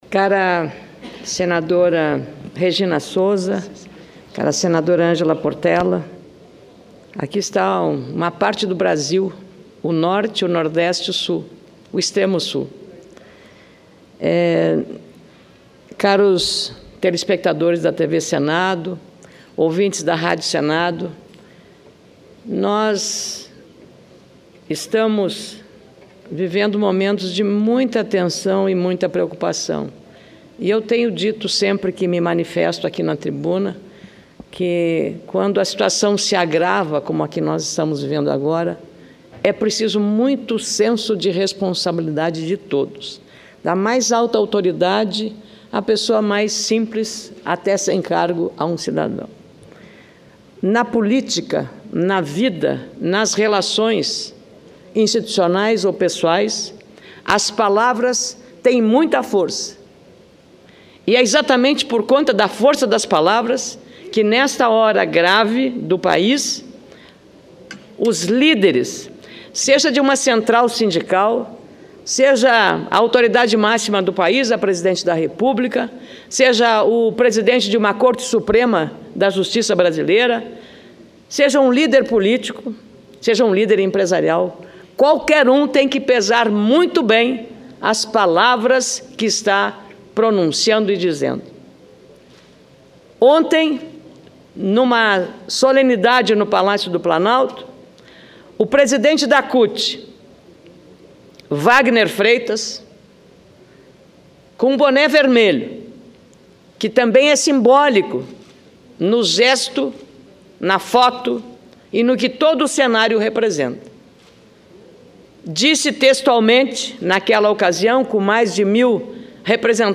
Plenário 2015
Discursos